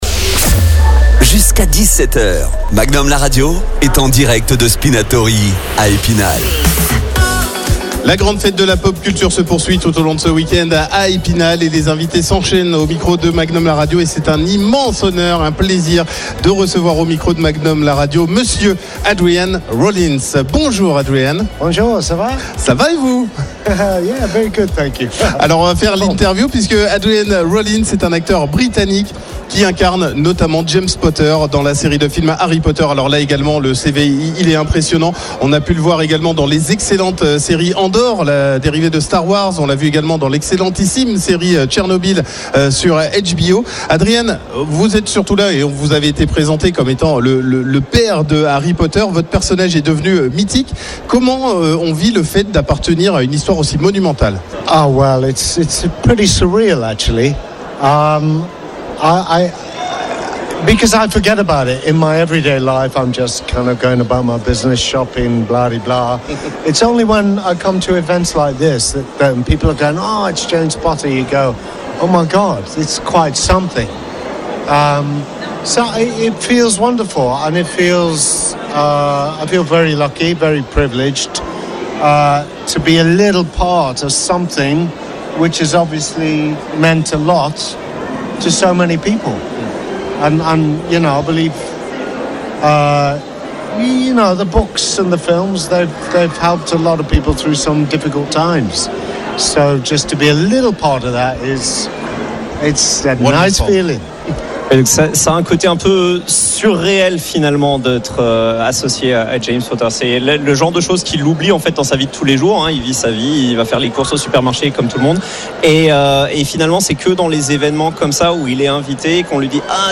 (la traduction des questions en direct a été coupée pour une meilleure fluidité d'écoute)
Sa présence à Spinatorii est une occasion rare de rencontrer un acteur que l’on voit peu en convention. Un moment privilégié pour Magnum la radio qui a pu échanger avec lui.